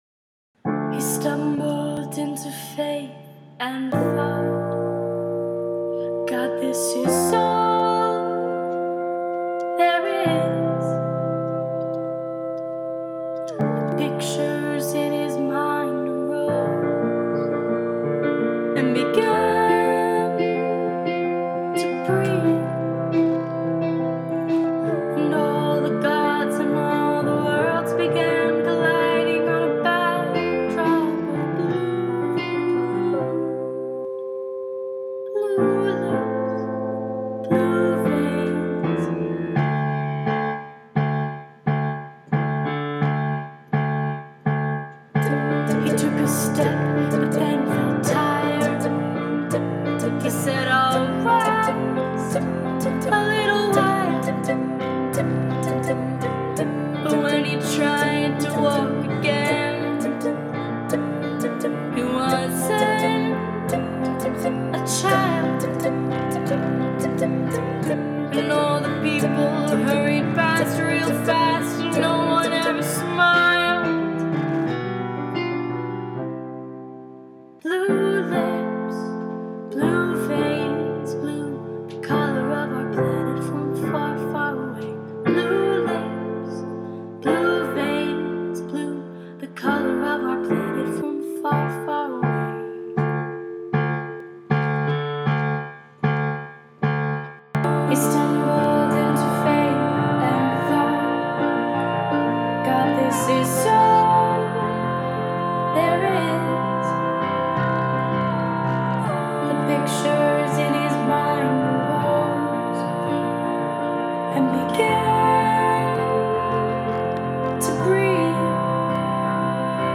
New Piano/Vocal Pop Arrangement
[I’m singing and playing all parts.]